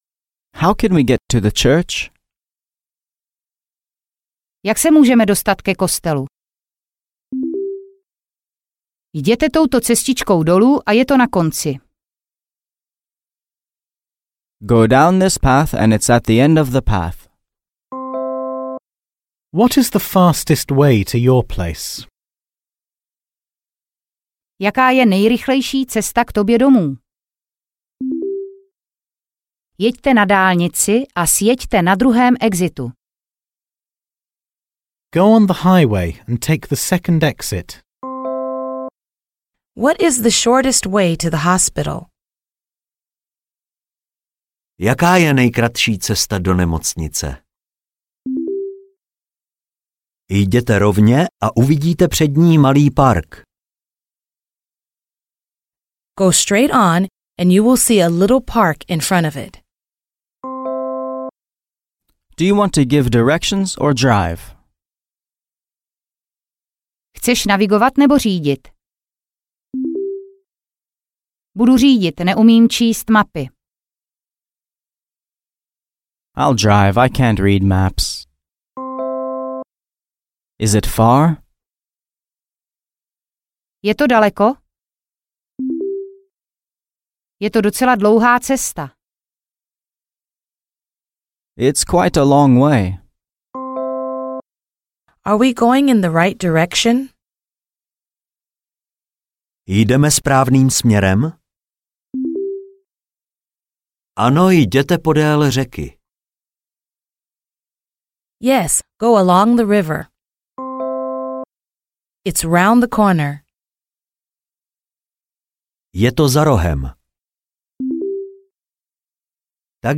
Audio knihaAngličtina do ucha 2 - mírně pokročilí
Ukázka z knihy